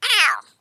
Hit Small Creature 2.wav